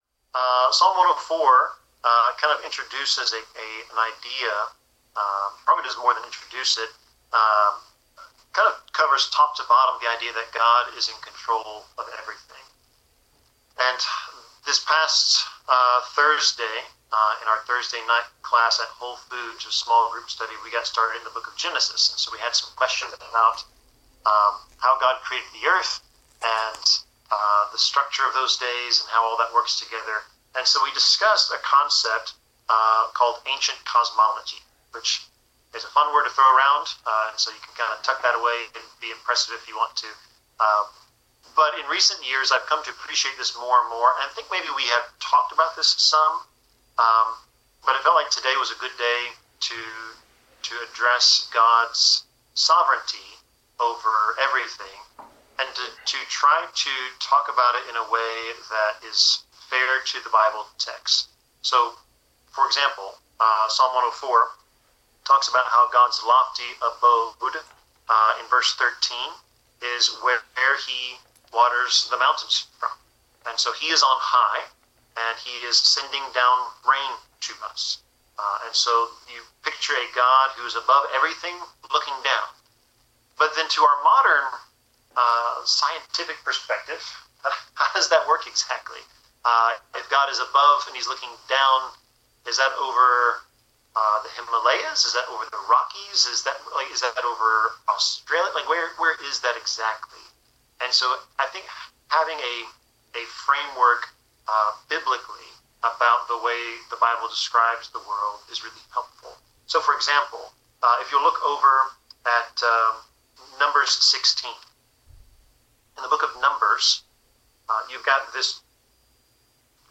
Passage: Psalm 104 Service Type: Sermon